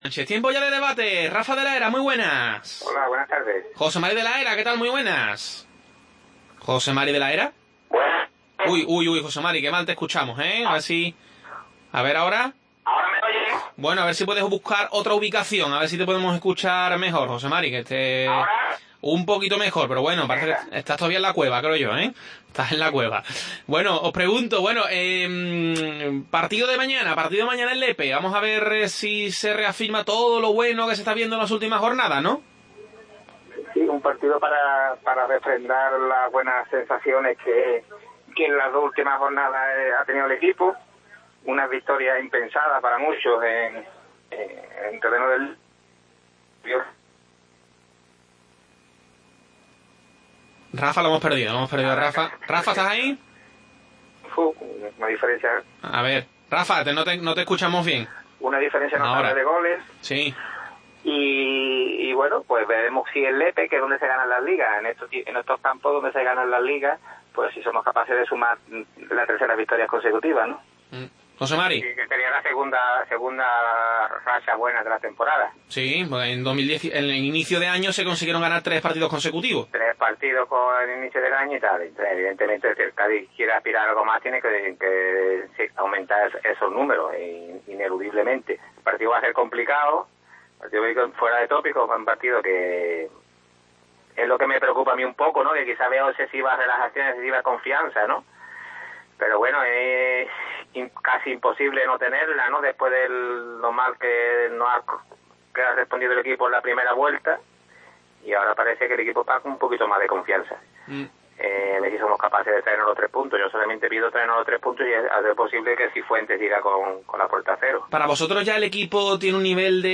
Tiempo de debate